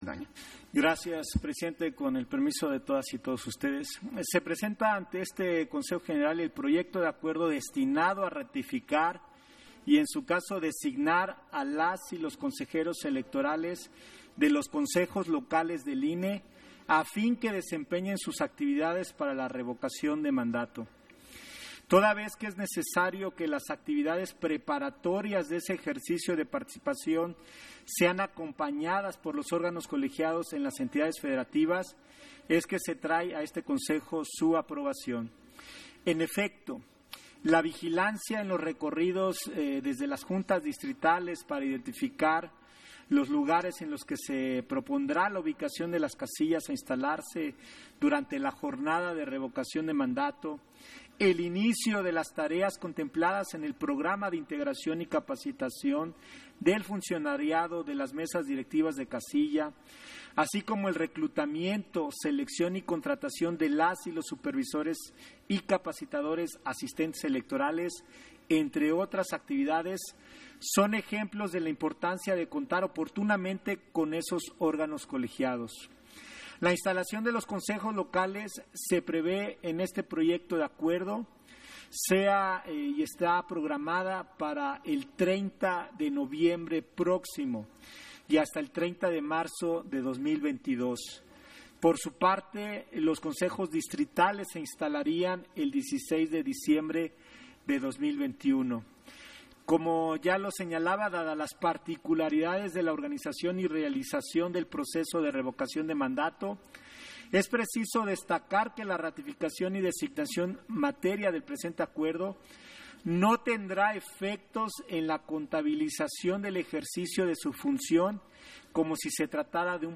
Intervención de José Roberto Ruiz, en Sesión extraordinaria, en el punto en que se ratifica y designa a Consejeras y Consejeros Electorales Locales, para la Revocación de Mandato 2022